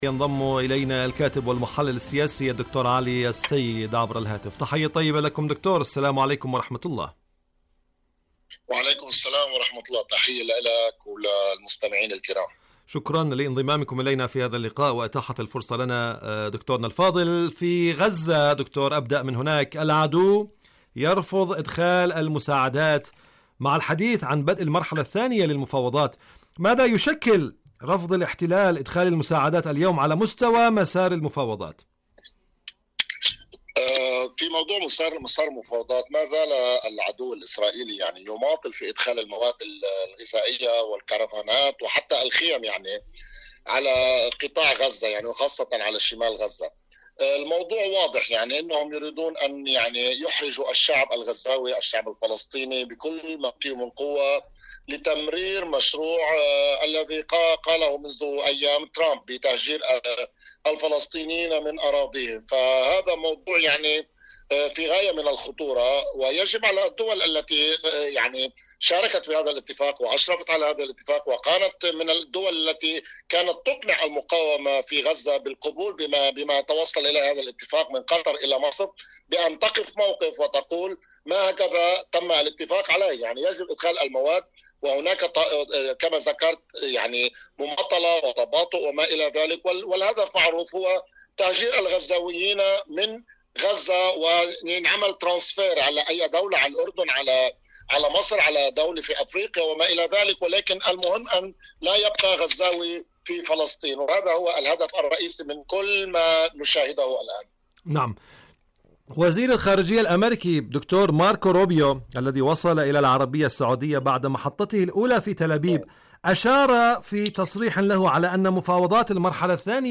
مقابلات برامج إذاعة طهران العربية برنامج حدث وحوار حدث وحوار مقابلات إذاعية واشنطن وجرعات الدعم للكيان الكيان الصهيوني الكيان الموقت شاركوا هذا الخبر مع أصدقائكم ذات صلة قوة الدبلوماسية والمواقف الإيرانية..
مقابلة